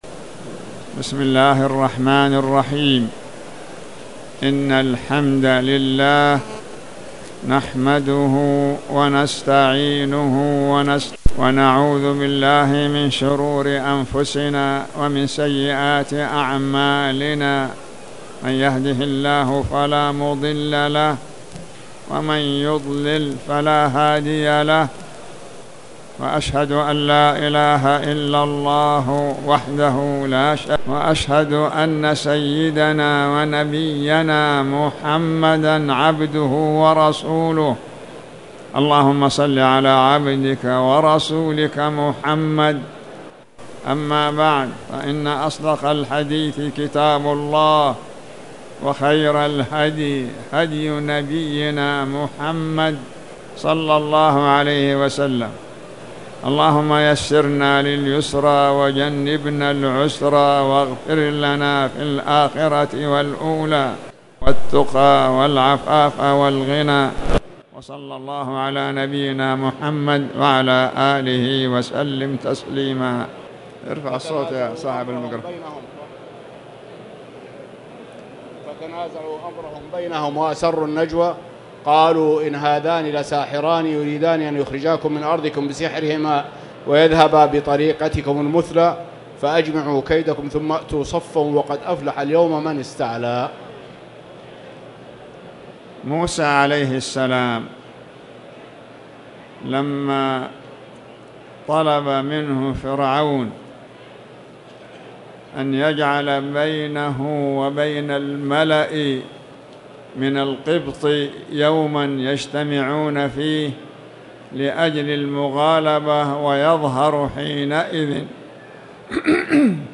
تاريخ النشر ٢٨ جمادى الآخرة ١٤٣٨ هـ المكان: المسجد الحرام الشيخ